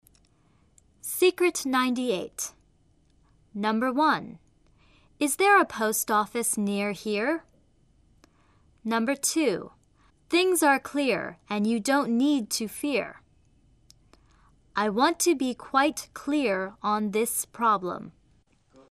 美音：［Iz  TZr   E   post  5RfIs  nIr   hIr］